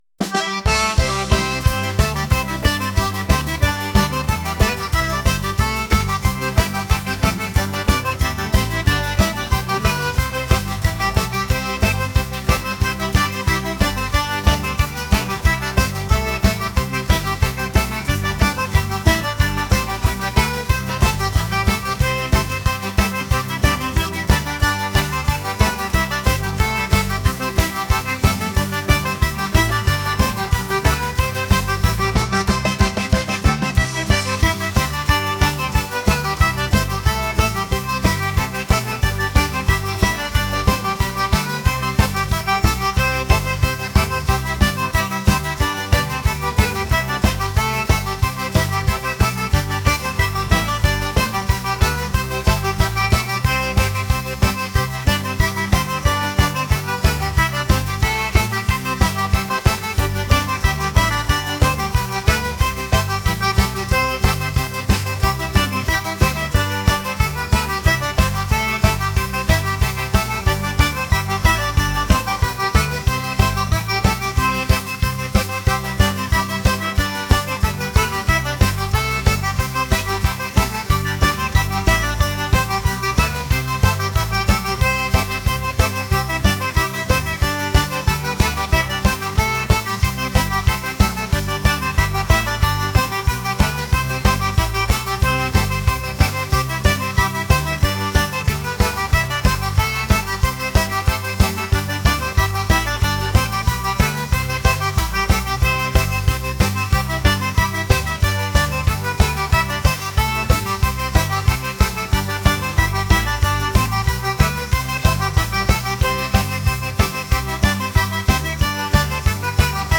lively